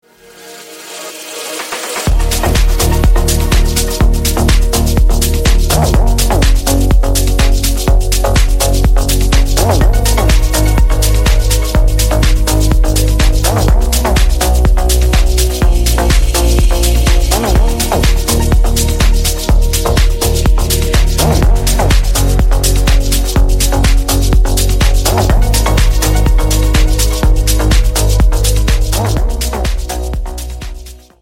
• Качество: 128, Stereo
Electronic
без слов
deep progressive
progressive house